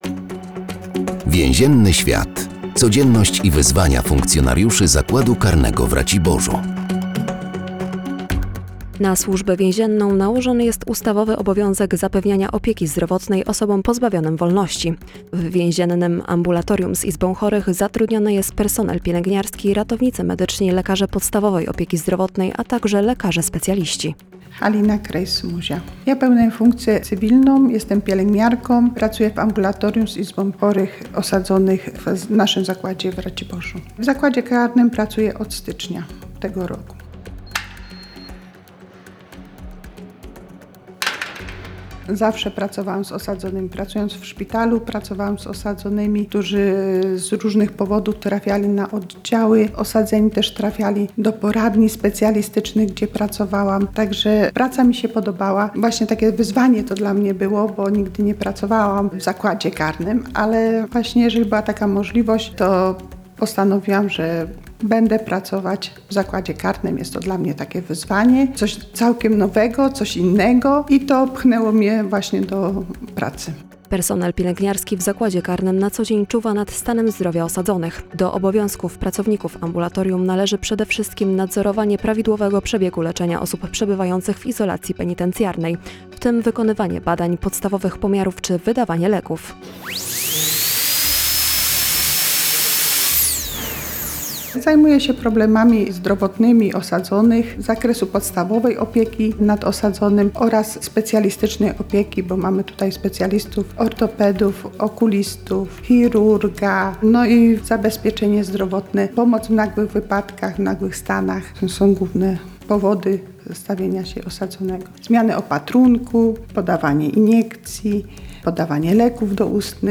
W każdym odcinku audycji “Więzienny świat” funkcjonariusze zakładu karnego w Raciborzu opowiadają o specyfice swojej pracy.